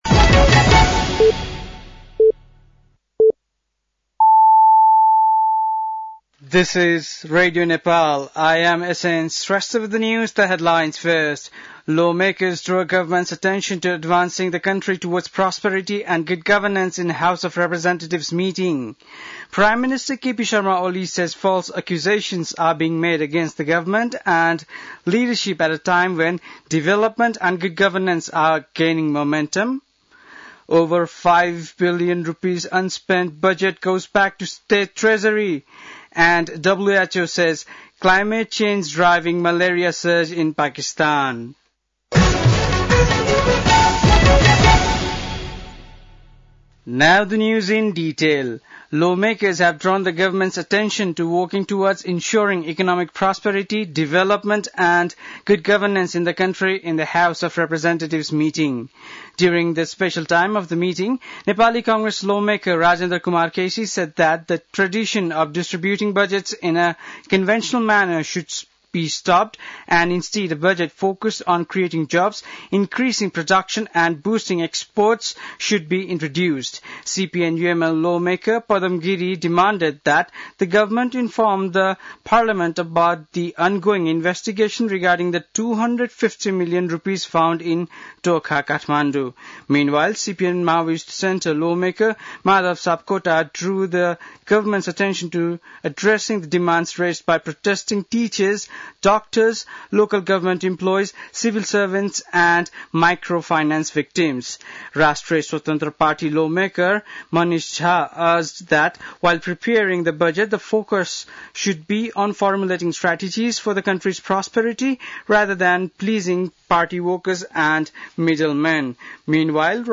बेलुकी ८ बजेको अङ्ग्रेजी समाचार : १४ वैशाख , २०८२
8-pm-english-news-.mp3